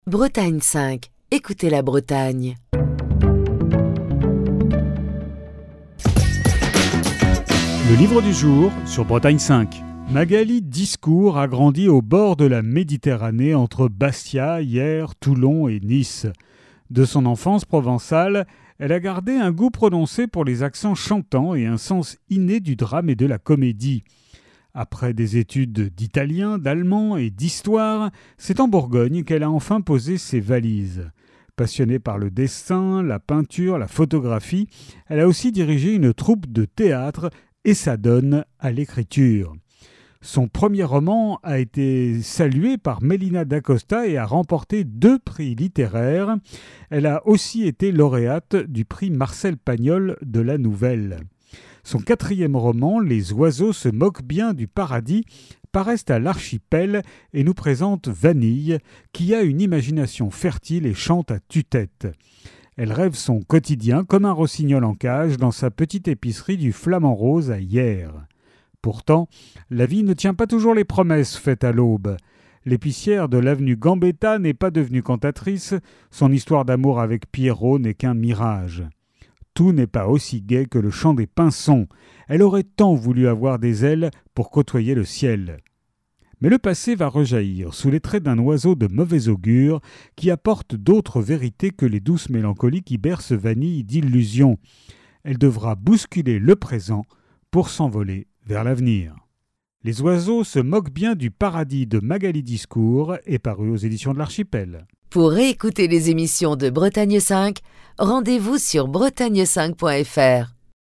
Chronique du 18 septembre 2024.